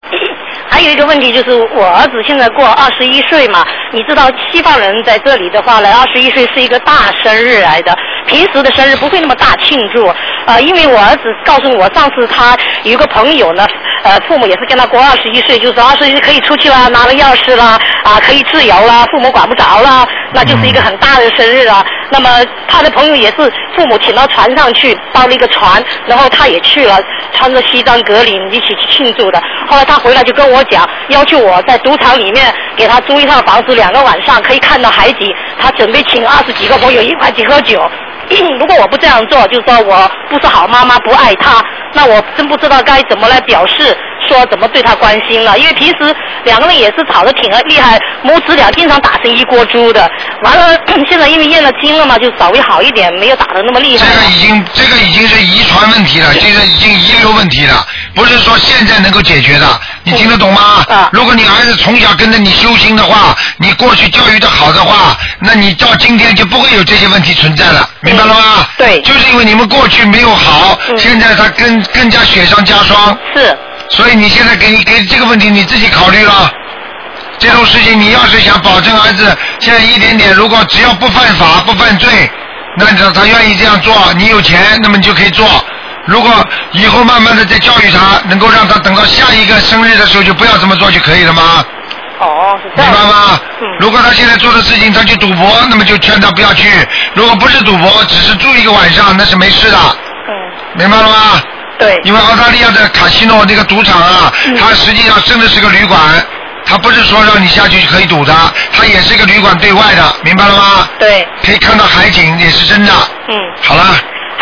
目录：2012年02月_剪辑电台节目录音集锦